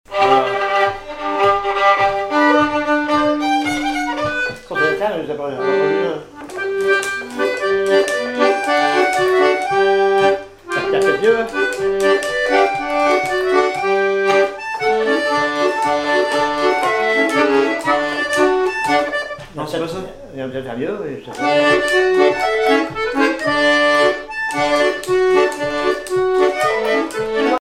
Miquelon-Langlade
danse : quadrille
Répertoire de bal au violon et accordéon
Pièce musicale inédite